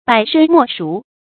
百身莫贖 注音： ㄅㄞˇ ㄕㄣ ㄇㄛˋ ㄕㄨˊ 讀音讀法： 意思解釋： 百身：自身死一百次；贖：抵。